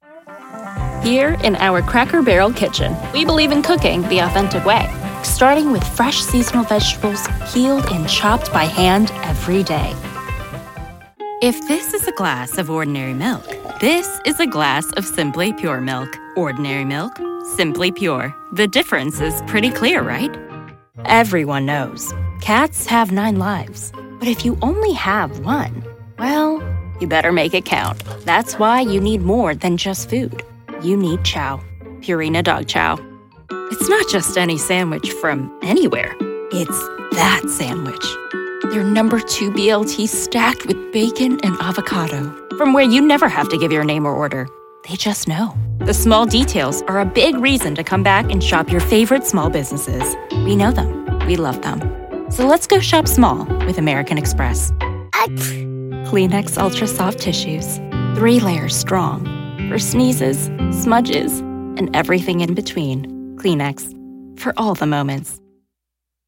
Voiceover
Women